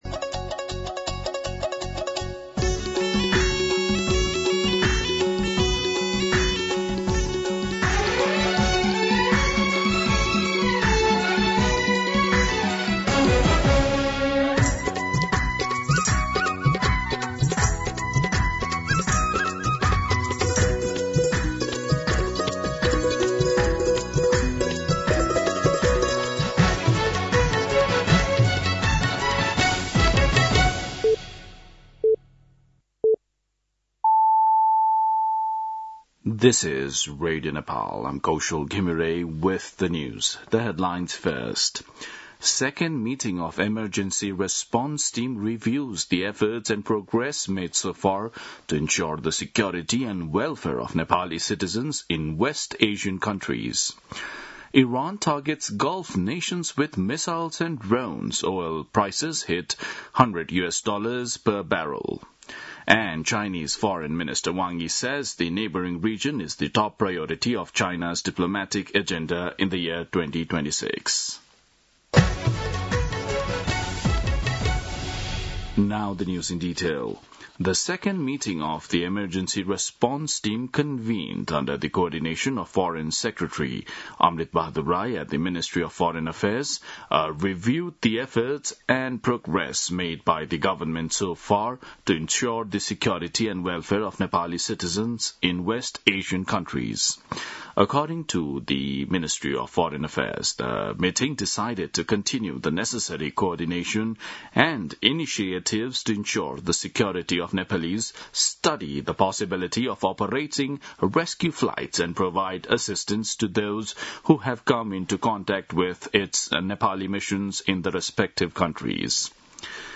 दिउँसो २ बजेको अङ्ग्रेजी समाचार : २८ फागुन , २०८२